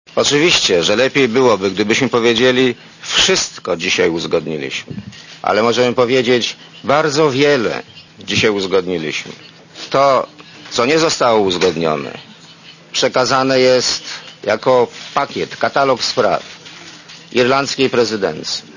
Brukseli nie stało się nic szczególnego - powiedział premier
Leszek Miller na konferencji w Brukseli.
Mówi premier Leszek Miller (74 KB)
miller_z_brukseli.mp3